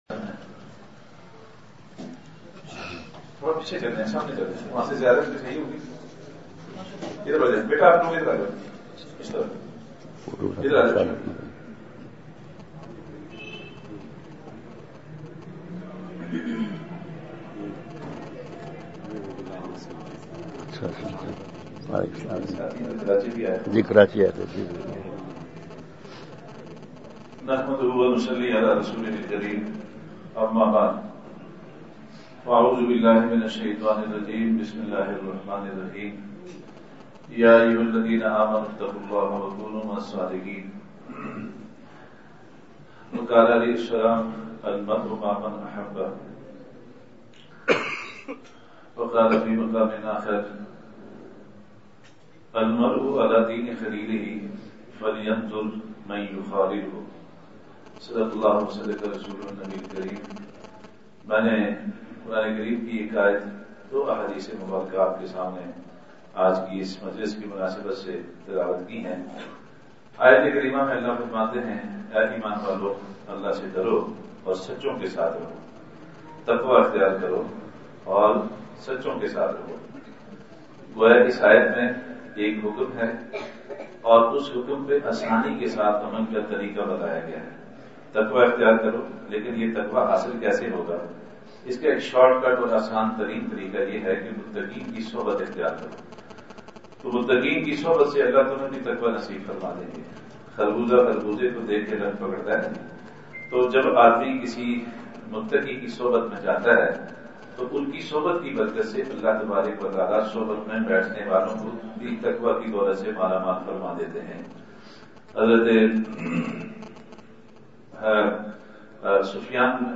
سفر پنجاب بیان دوپہر ۲۶ / اکتوبر ۲۵ء:مقدس مقامات کو تصویر کے گناہ سے پاک رکھیں
*بمقام:۔جامعہ دارالعلوم محمدیہ صادق آباد راولپنڈی*